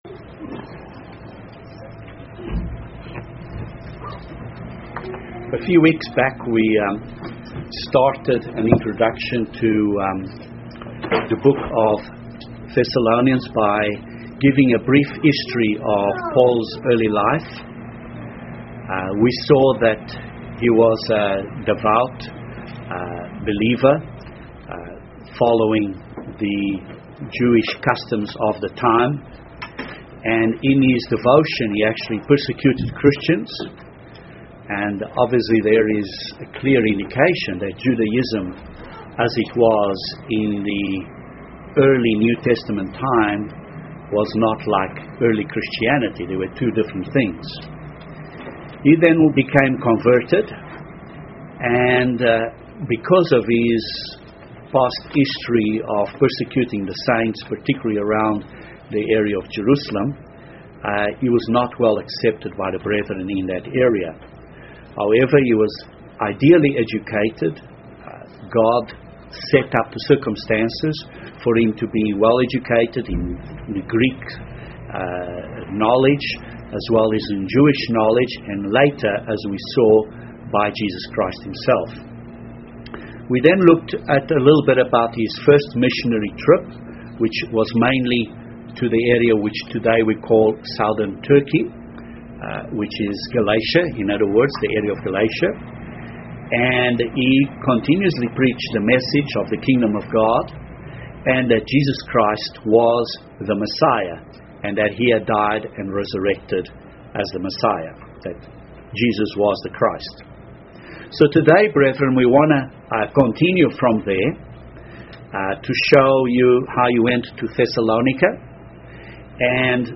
This Bible study covers Paul's visit to Thessalonica and demonstrates the issue which triggered the trials of the brethren in Thessalonica.